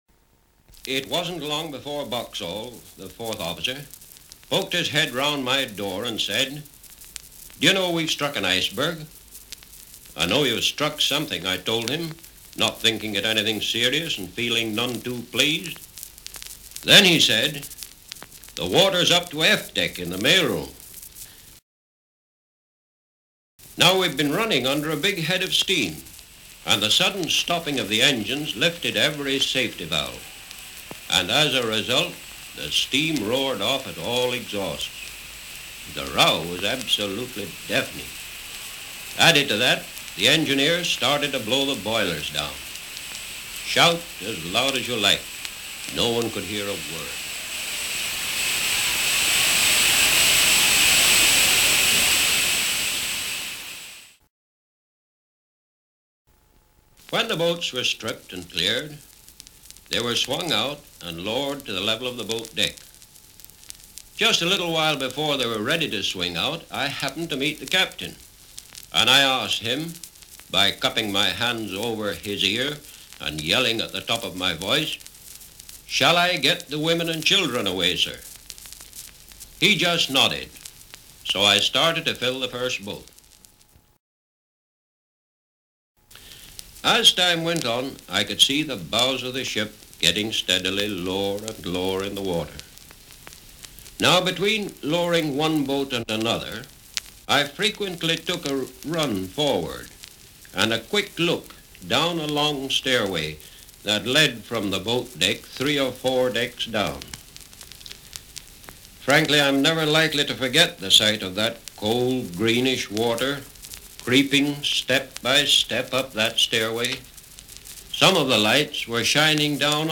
Qui potete ascoltare le voci di chi visse, in prima persona, il disastro del TITANIC nel 1912. Queste registrazioni originali sono state gentilmente concesse dall'Archivio della BBC.
Charles Herbert Lightoller, Secondo Ufficiale del TITANIC;